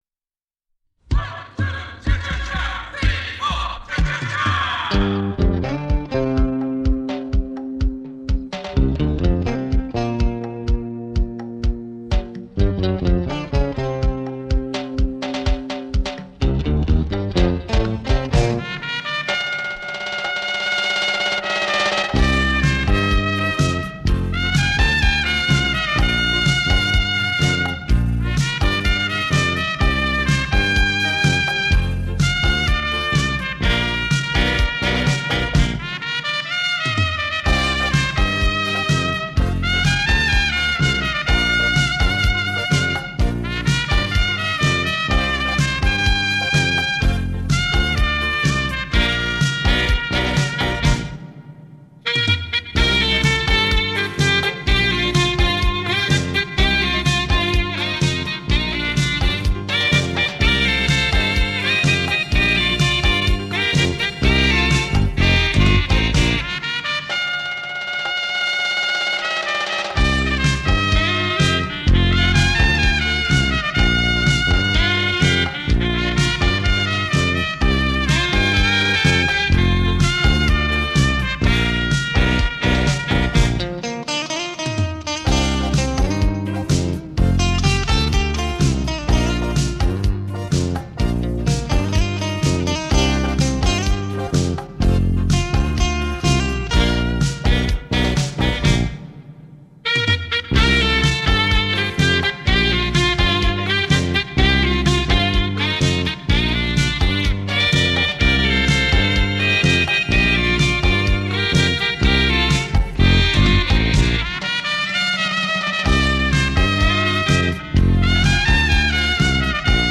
小号
萨克士